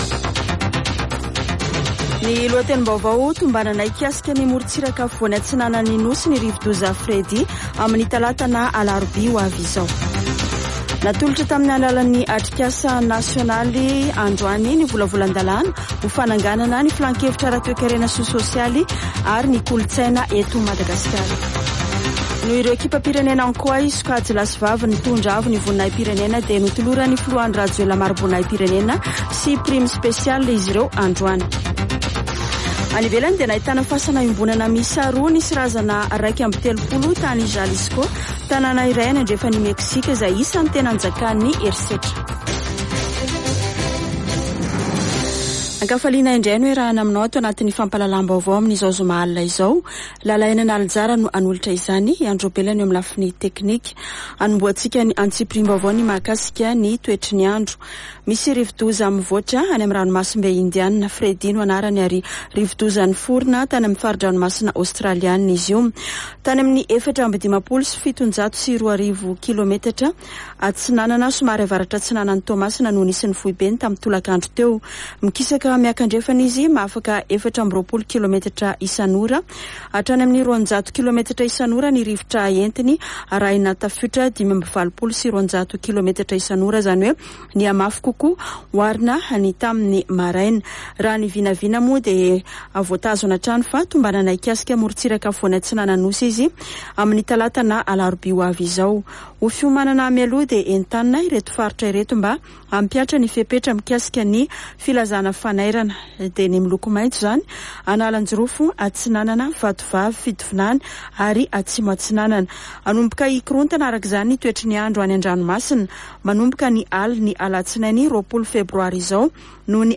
[Vaovao hariva] Zoma 17 febroary 2023